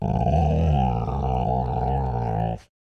Minecraft Version Minecraft Version 1.21.5 Latest Release | Latest Snapshot 1.21.5 / assets / minecraft / sounds / mob / wolf / grumpy / growl2.ogg Compare With Compare With Latest Release | Latest Snapshot
growl2.ogg